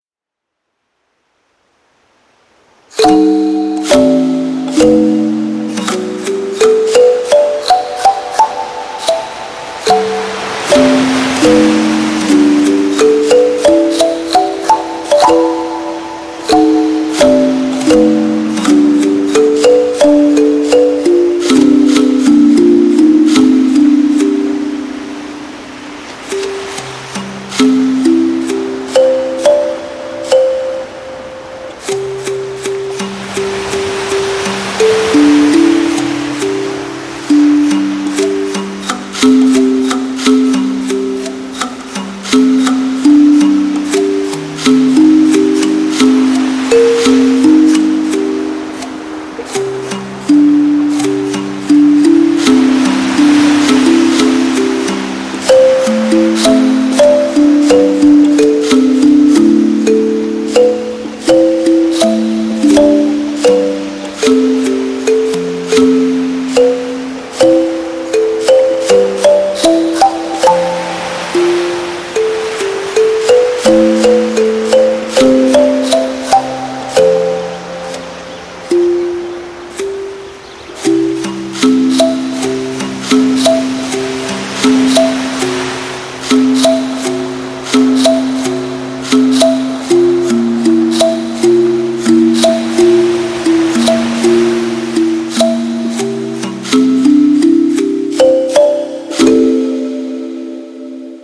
• Жанр: Детские песни
🎶 Детские песни / Музыка детям 🎵 / Музыка для новорожденных